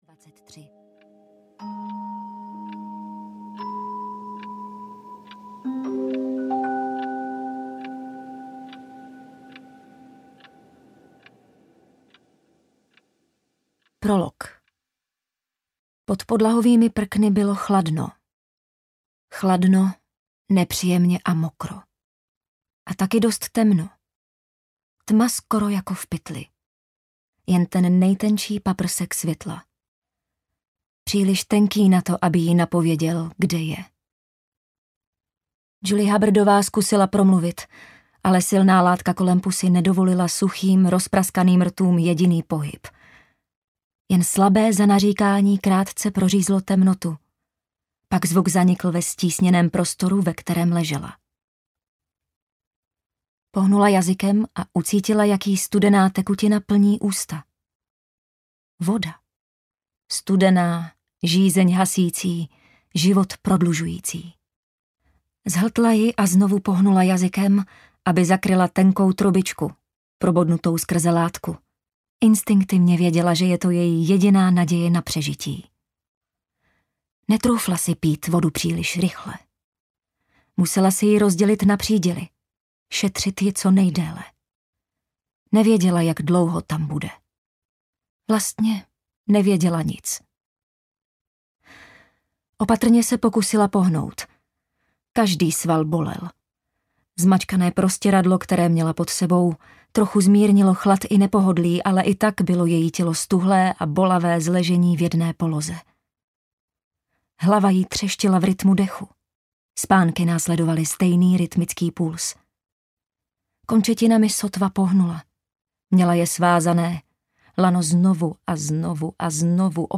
Ukázka z knihy
mamincin-mazanek-audiokniha